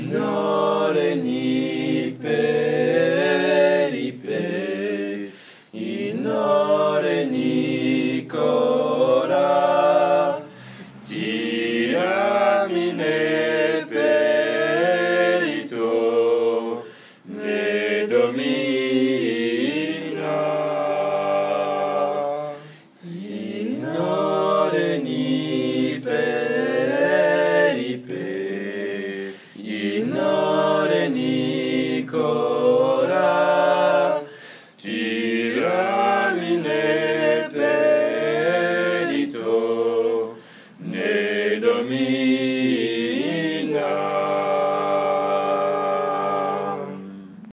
A capella, le retour